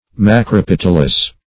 Search Result for " macropetalous" : The Collaborative International Dictionary of English v.0.48: Macropetalous \Mac`ro*pet"al*ous\, a. [Macro- + petal.]